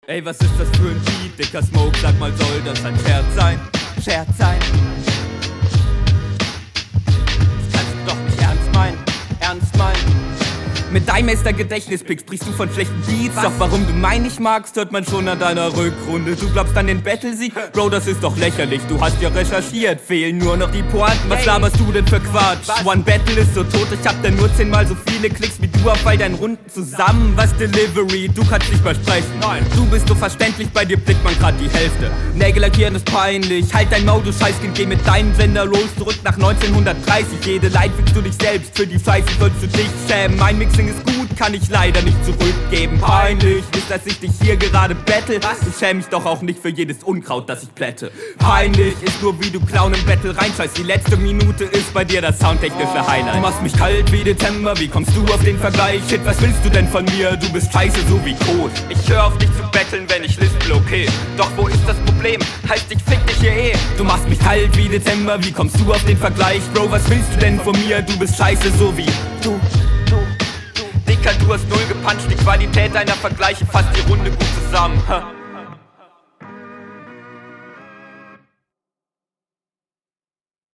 Klingt wieder viel cleaner als der Gegner.